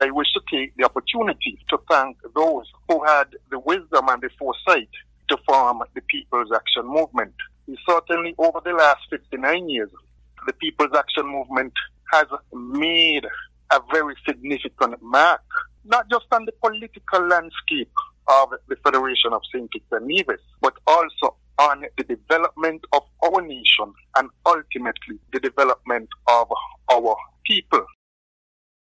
PAM (People’s Action Movement) celebrated its 59th anniversary on Friday Jan. 19th and its Political Leader, the Hon. Shawn Richards, gave the VONNEWSLINE this comment: